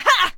attack1.ogg